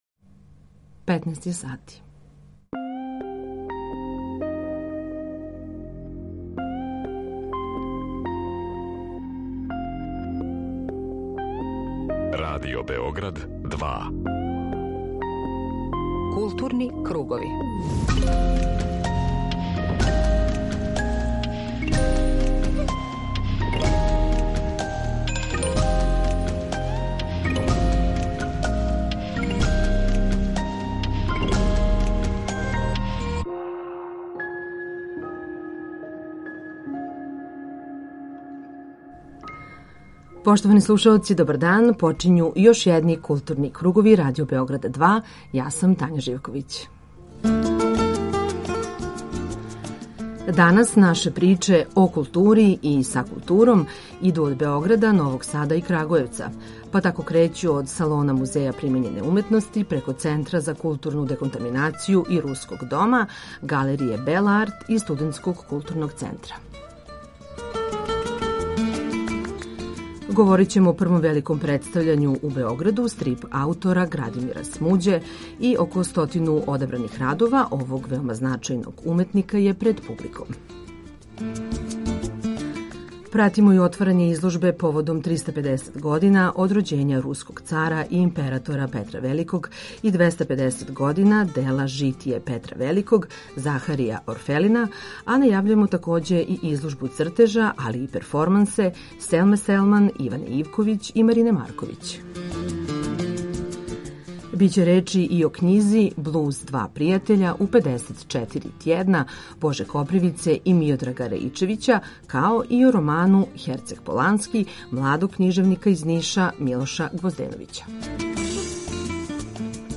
Централна културно-уметничка емисија Радио Београда 2.